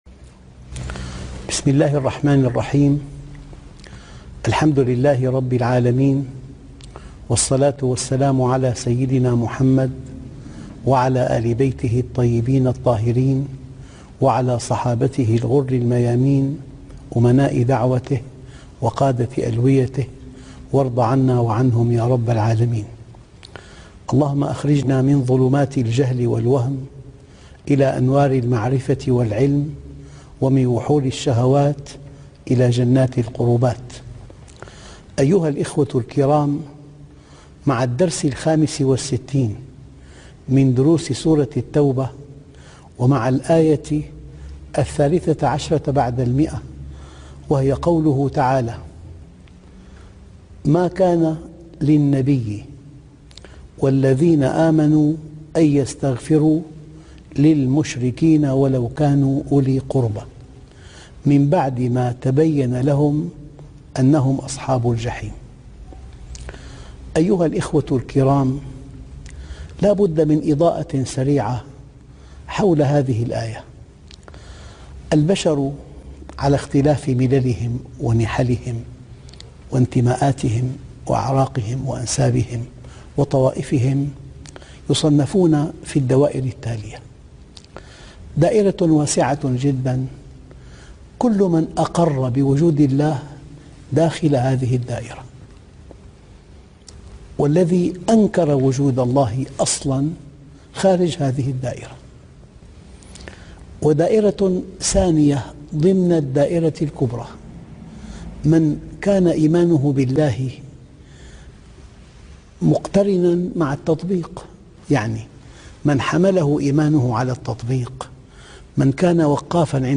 الدرس ( 65) تفسير سورة التوبة - الشيخ محمد راتب النابلسي